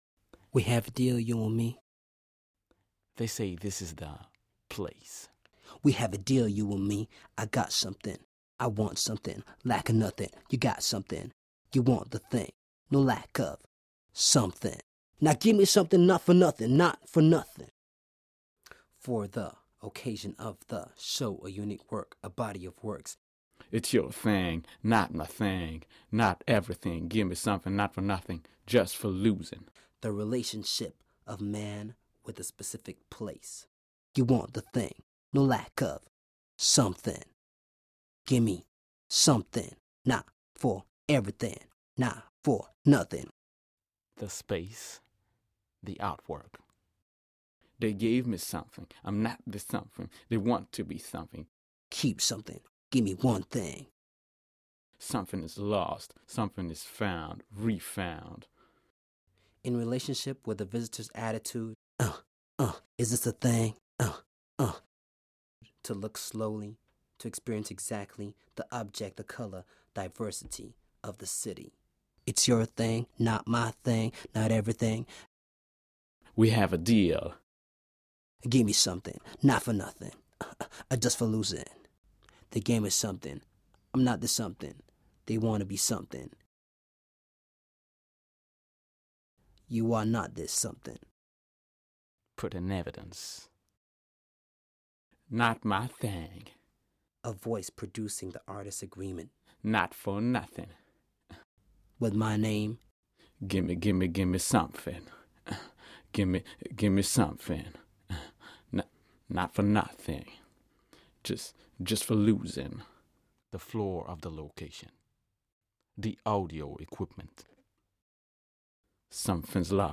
CURRENT DEAL is a spoken word piece. It is based on the text of the contract agreed between the artists and the exhibition institution.
The contract is spoken by a rapper in his own rhythm. This voice is crossed by another rapper, who speaks a text concerning the agreement between the artist and the rappers, whom she had hired as a speakers.
3 CDs, Installation with 5 sound sources, in the show THEY SAY THIS IS THE PLACE, Queen Fabiola Hall, Antwerpen
Current Deal sound piece